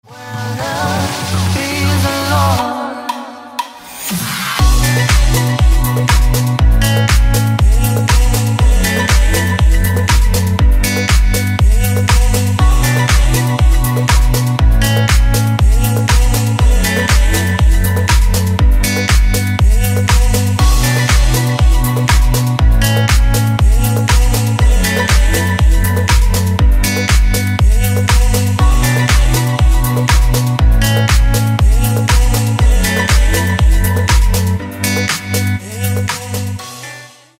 deep house
dance
Electronic
club